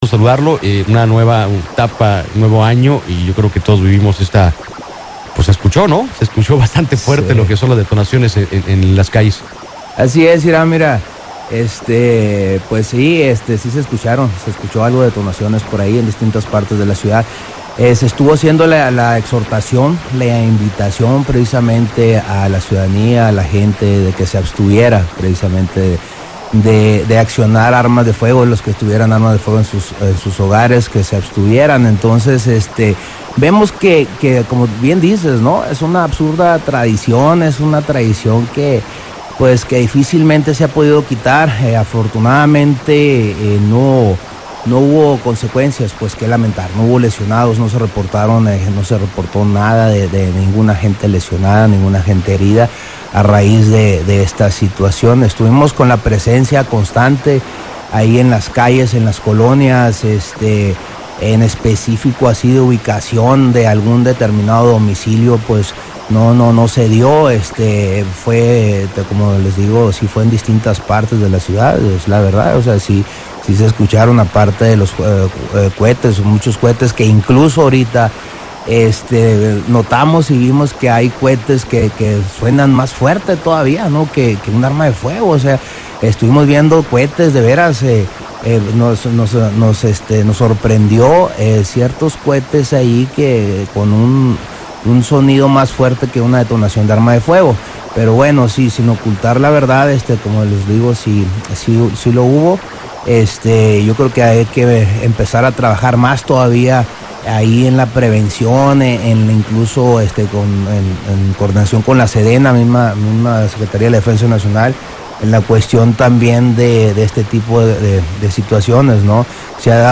El comandante Ernesto Fernando Fernández Portillo declaró en entrevista para el noticiero de Radio Amor 107.9 FM, que este tipo de acciones representan una tradición que difícilmente se puede quitar, aunque afortunadamente no hubo lesionados.